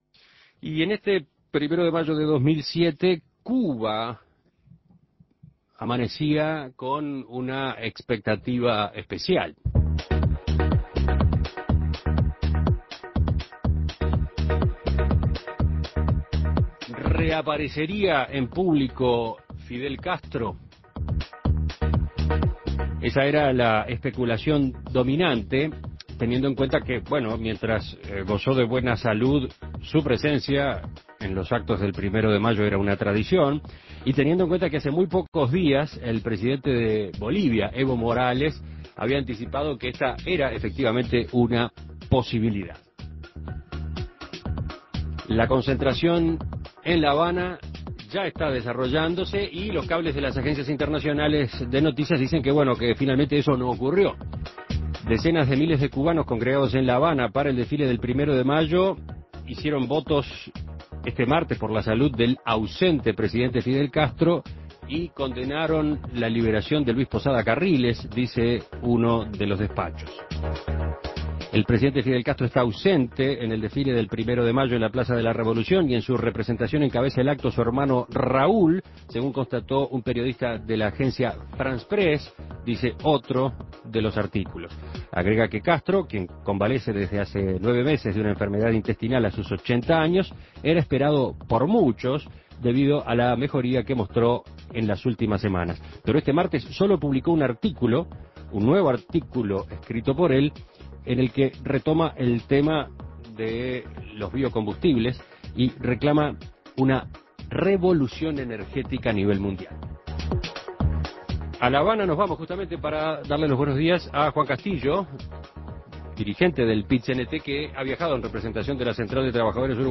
Entrevistas Fidel no participó de los actos en Cuba Imprimir A- A A+ Se especuló con que este 1° de Mayo podría suponer la vuelta de Fidel Castro al poder.